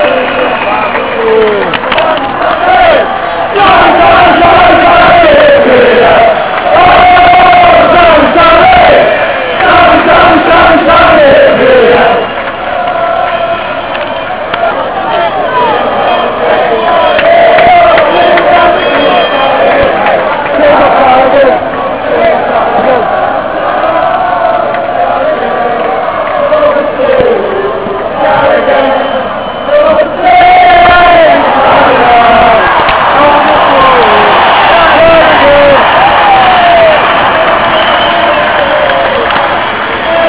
THEME > SUPPORTERS + chants de supporters enregistrés dans les tribunes (fichier mp3